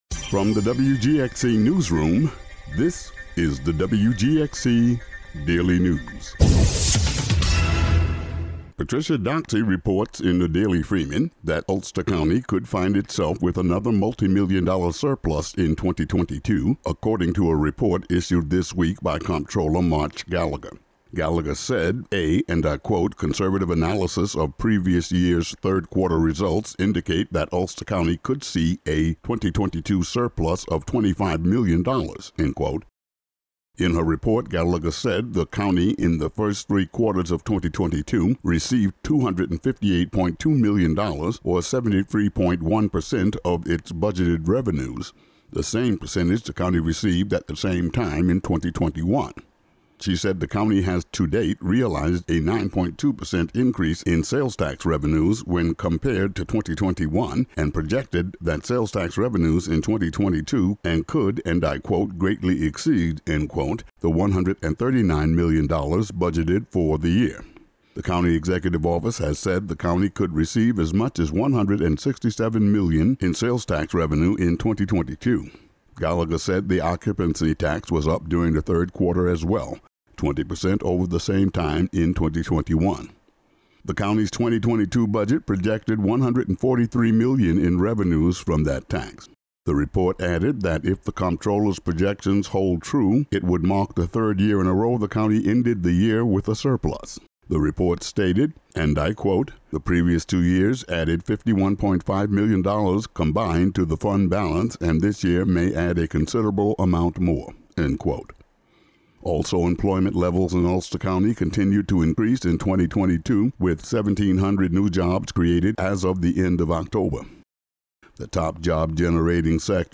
Today's daily local news.